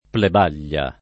plebaglia [ pleb # l’l’a ] s. f.